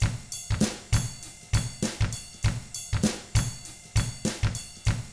99FUNKY4T2-L.wav